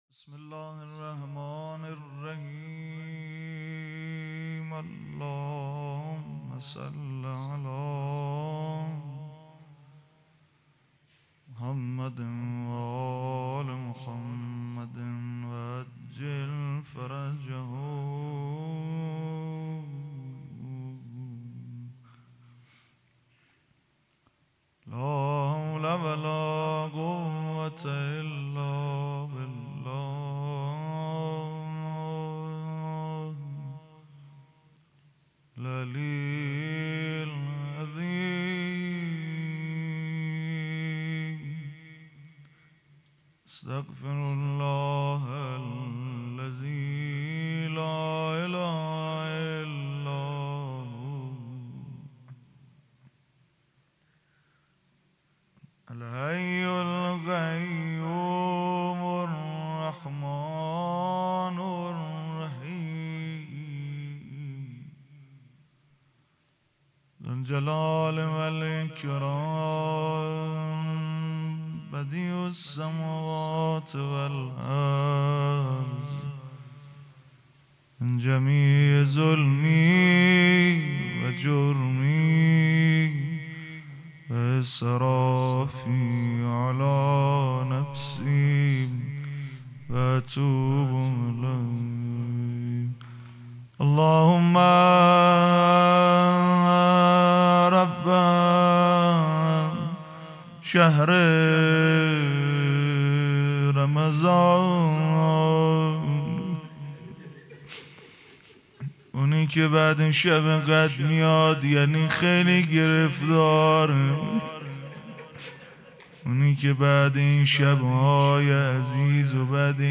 سخنرانی: دوگانه‌ی ایمان و عمل